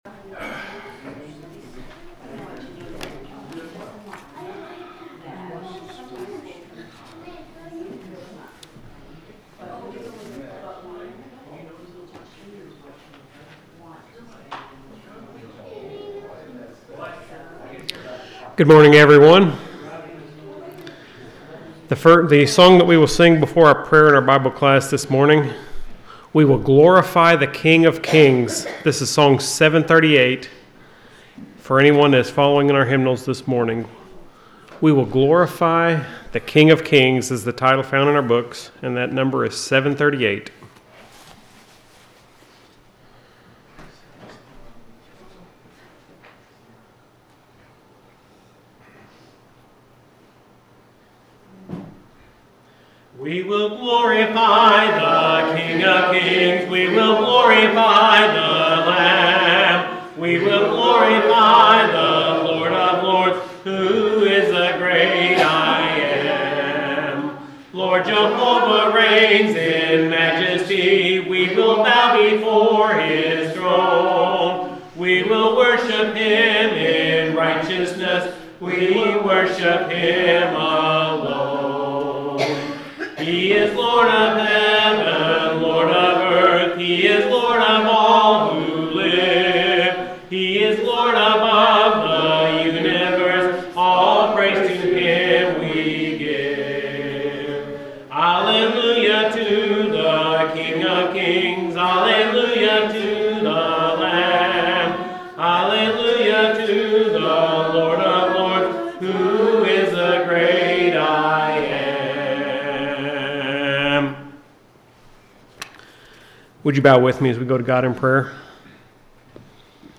The sermon is from our live stream on 11/16/2025